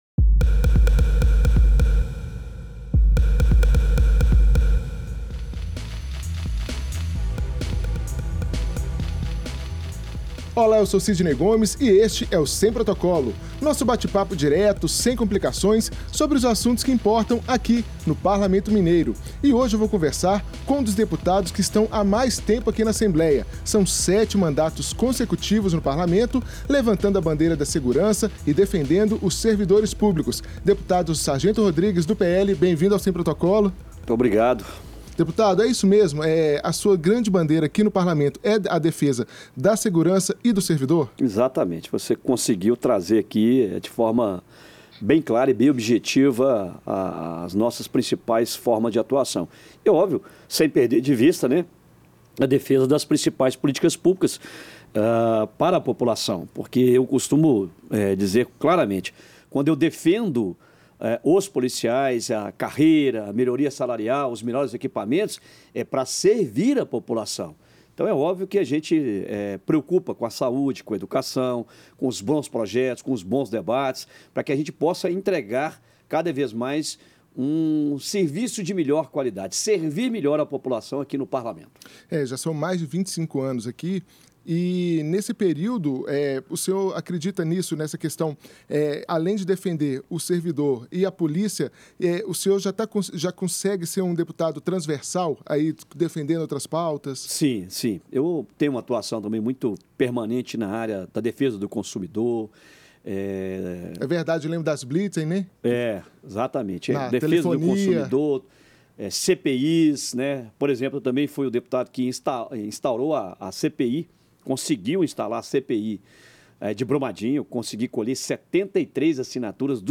Os sete mandatos consecutivos na Assembleia Legislativa dão ao deputado Sargento Rodrigues (PL) a experiência para falar sobre qualquer assunto. Mas, para ele, a pauta da segurança pública é especial desde o ano de 1990, quando assumiu o primeiro mandato. Nesta conversa